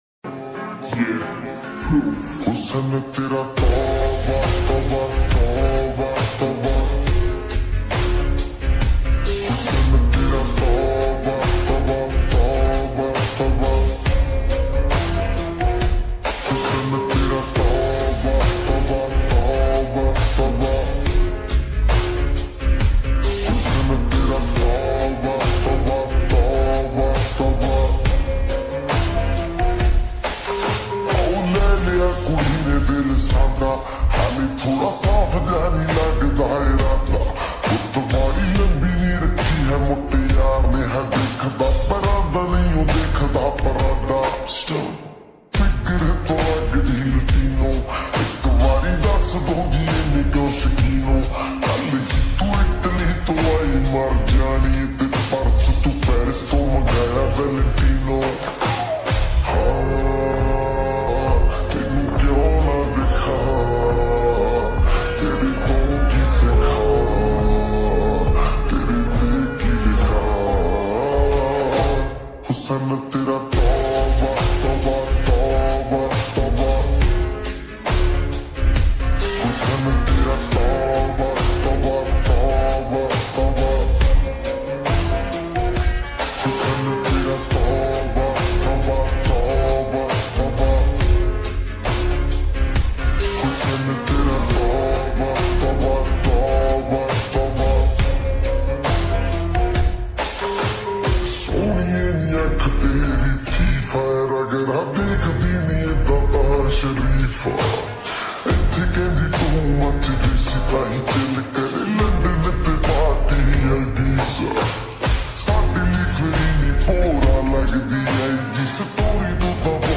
SLOWED AND REVERB FULL SONG PUNJABI